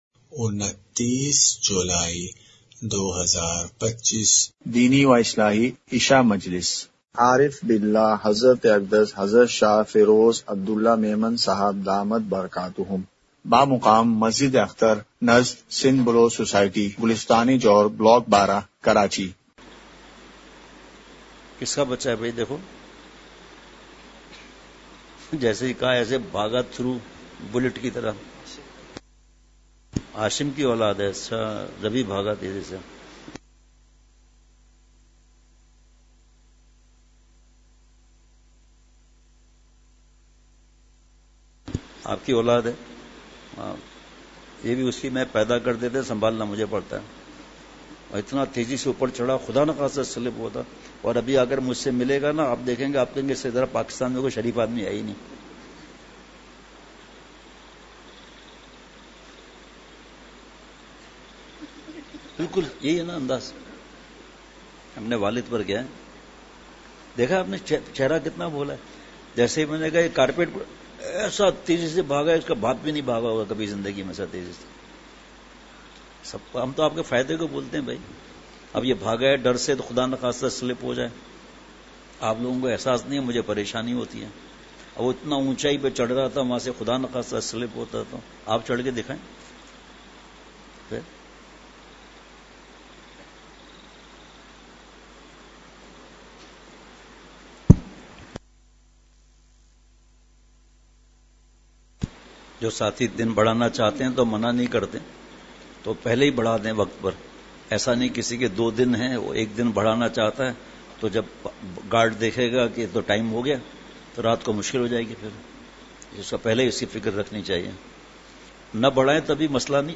مقام:مسجد اختر نزد سندھ بلوچ سوسائٹی گلستانِ جوہر کراچی